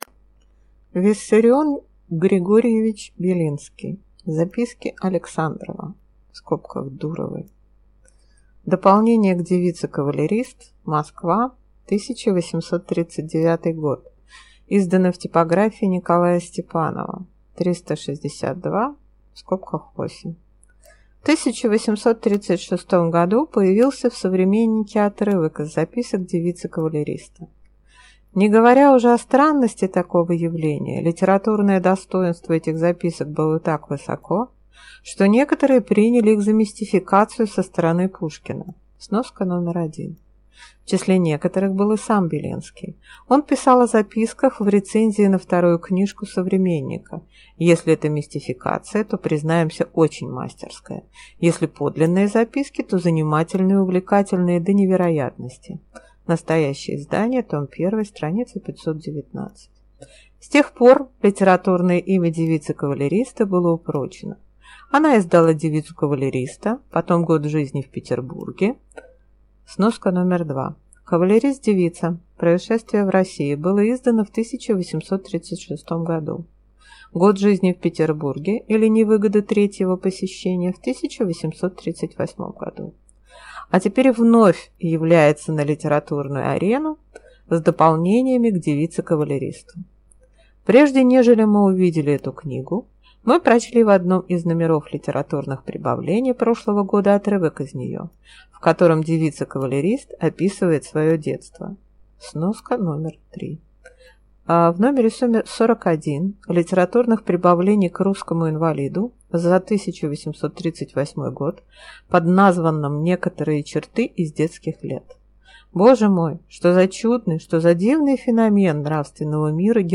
Аудиокнига Записки Александрова (Дуровой)…